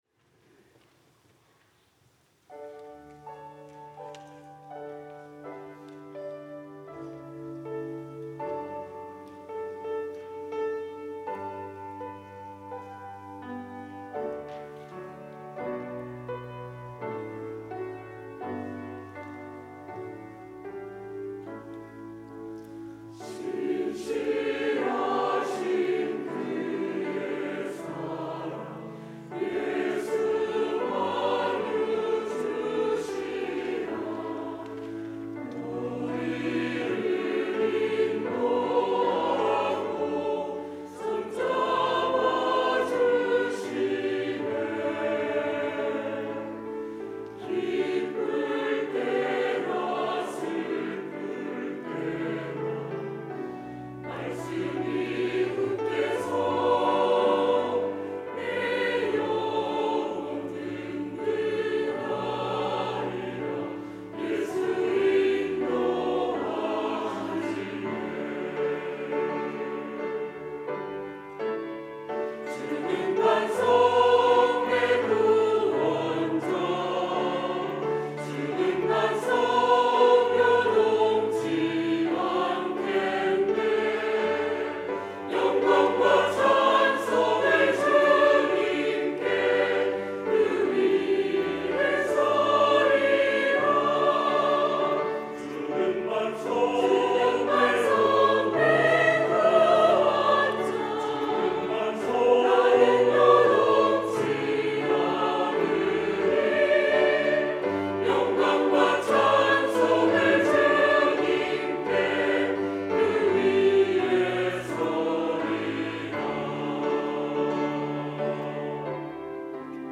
할렐루야(주일2부) - 반석 위에 서리
찬양대